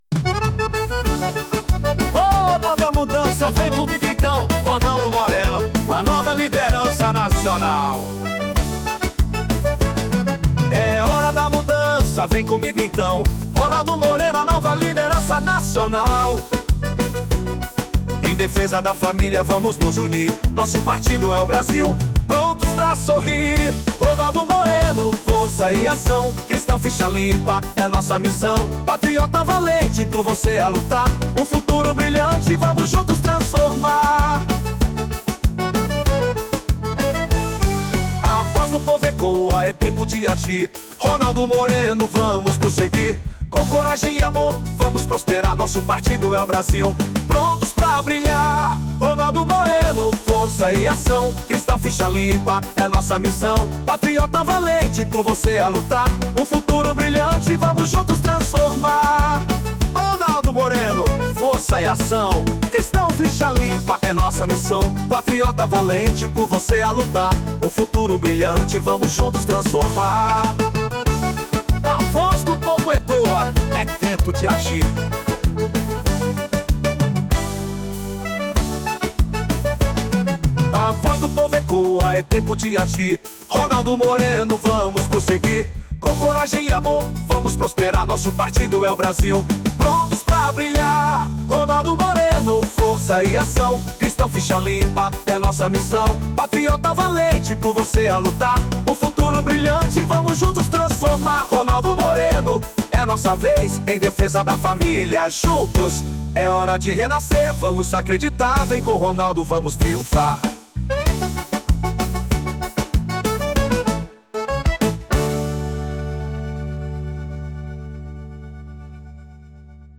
Jingle sob titulo: Nosso Partido é o Brasil !